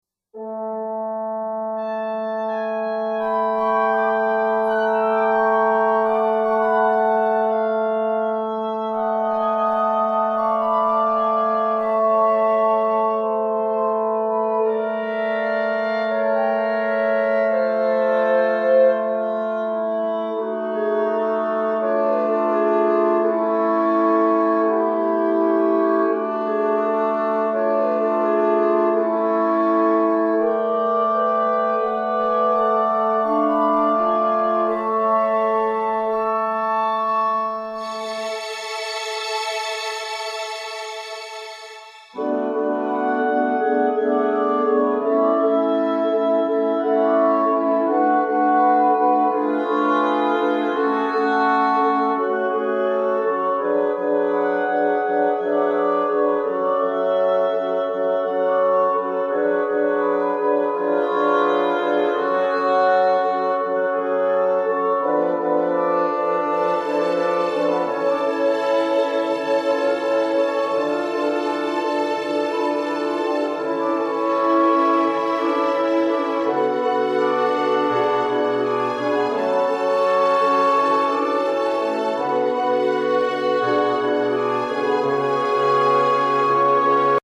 強弱もつけてないし、アーティキュレーションも変ですが、まあフルオーケストラなので。